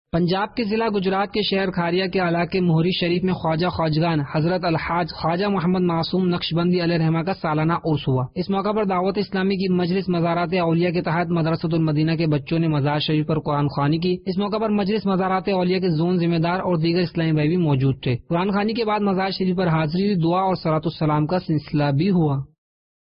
News Clip Urdu - 25 November 2019 - Hazrat Alhaj Khuwaja Mohammad Masoom Naqshbandi علیہ الرحمہ Ka Salana Urs Dec 31, 2019 MP3 MP4 MP3 Share حضرت الحاج خواجہ محمد معصوم نقشبندی علیہ الرحمہ کا سالانہ عرس